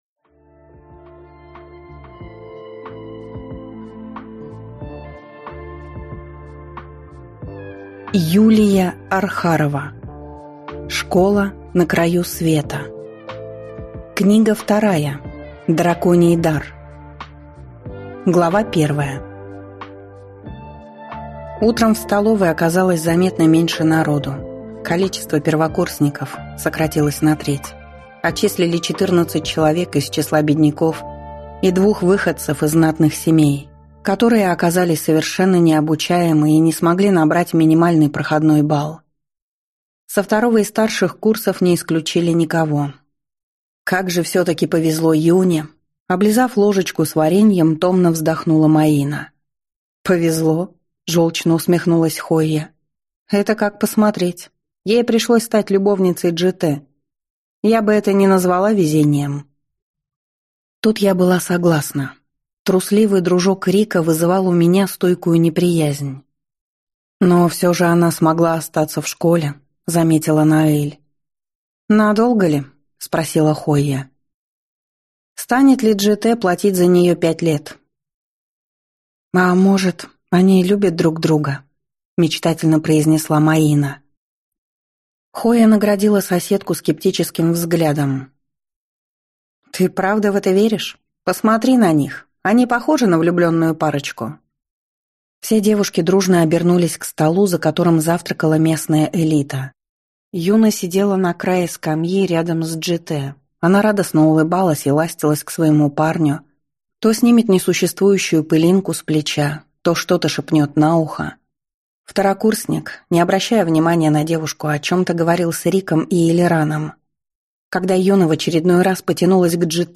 Аудиокнига Школа на краю света. Драконий дар | Библиотека аудиокниг